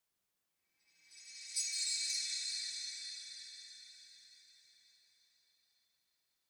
Minecraft Version Minecraft Version snapshot Latest Release | Latest Snapshot snapshot / assets / minecraft / sounds / ambient / nether / crimson_forest / shine3.ogg Compare With Compare With Latest Release | Latest Snapshot